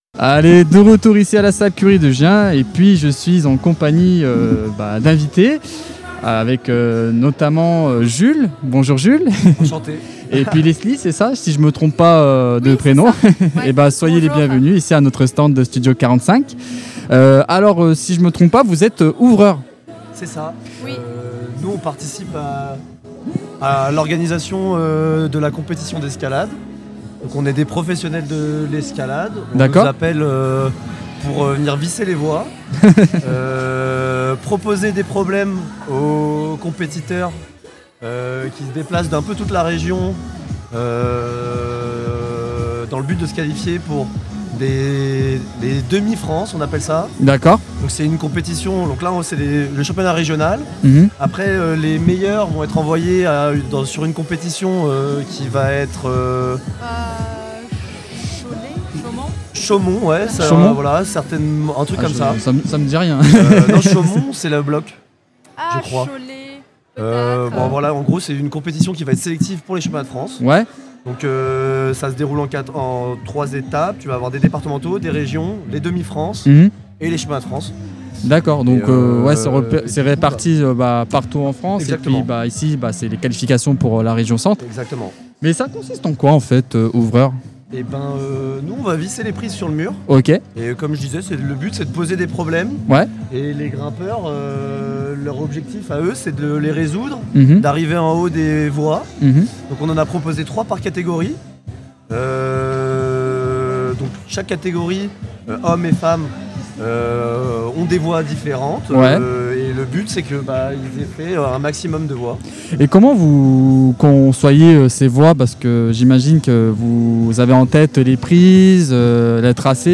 Studio 45 vous emmène au cœur de la compétition d’escalade en difficulté à Gien